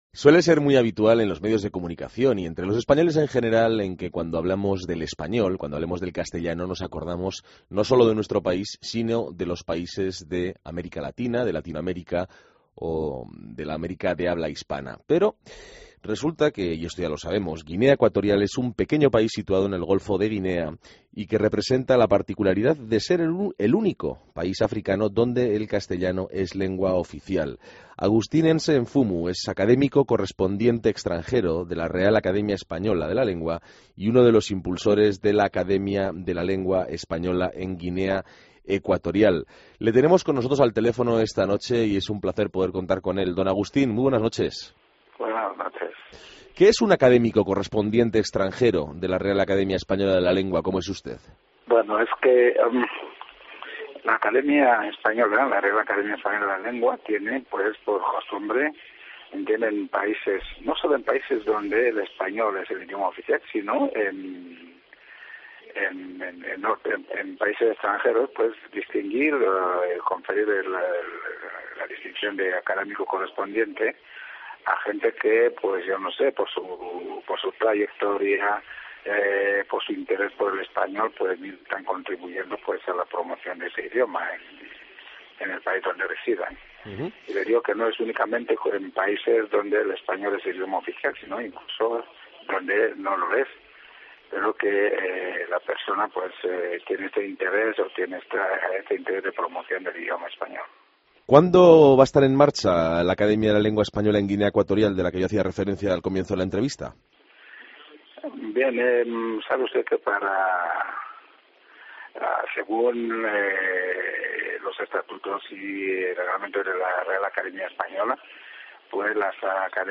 Hablamos con Agustín Nze Nfumu, Académico Correspondiente Extranjero de la Real Academia Española de la Lengua, impulsor de la RAE en Guinea Ecuatorial.